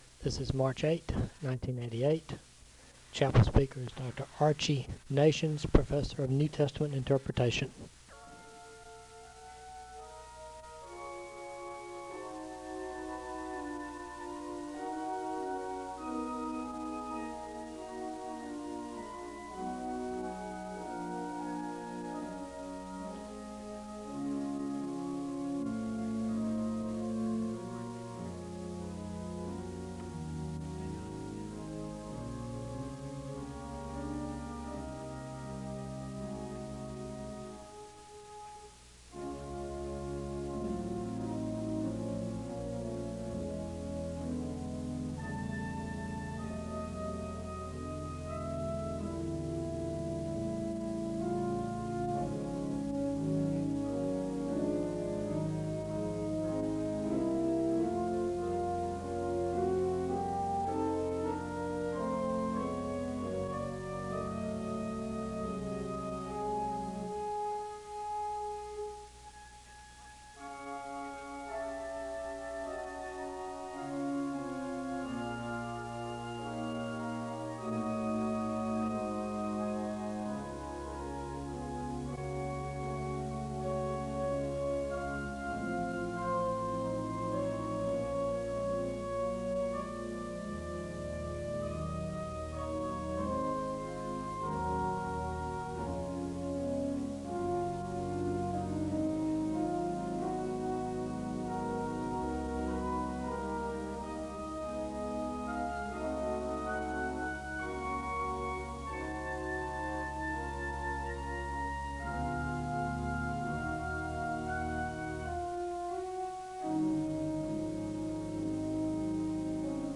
The service begins with organ music (0:00-4:35). Prayer concerns are shared and there is a moment of prayer (4:36-8:28). There is a Scripture reading (8:29-9:05).